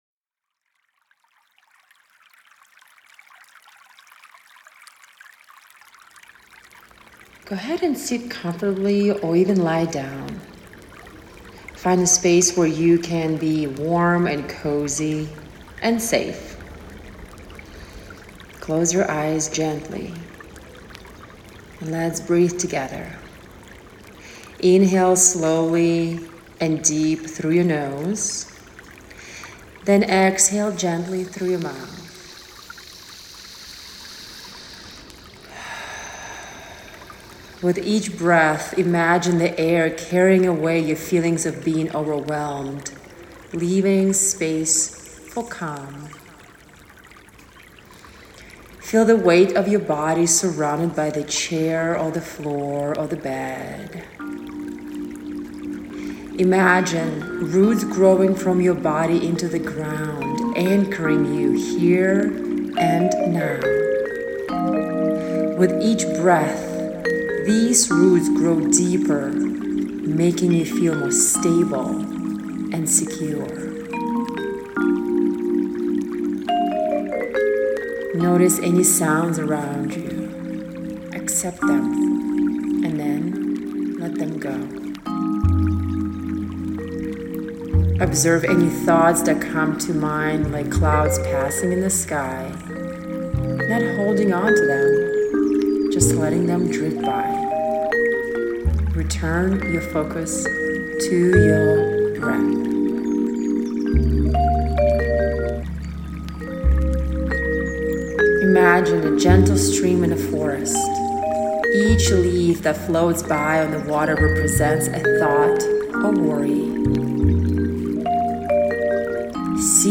Once recorded, our musicians infuse the mini with unique, powerful music, capturing its essence.